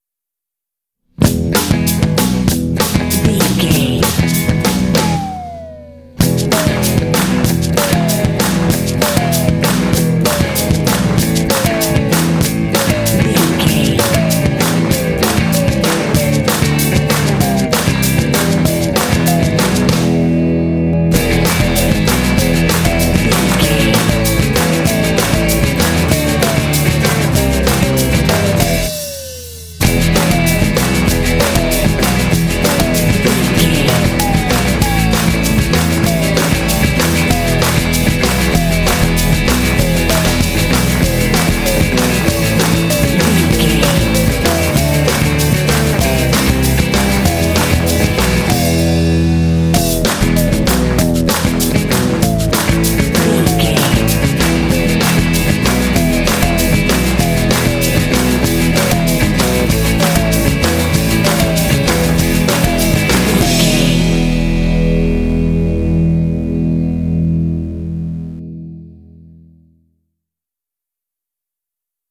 Uplifting
Ionian/Major
bouncy
happy
electric guitar
drums
bass guitar
surf